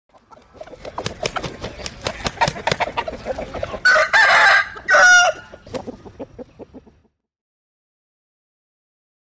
Tiếng Gà vỗ cánh phành phạch và Gáy…
Thể loại: Tiếng vật nuôi
Description: Tiếng gà trống vỗ cánh phành phạch, phạch phạch, đập cánh rào rạt rồi gáy ò ó o vang vọng, lảnh lót, dõng dạc chào đón bình minh. Nhịp cánh liên hồi hòa cùng tiếng gáy inh ỏi, ngân dài, khơi dậy bầu không khí làng quê tinh khôi.
tieng-ga-vo-canh-phanh-va-gay-www_tiengdong_com.mp3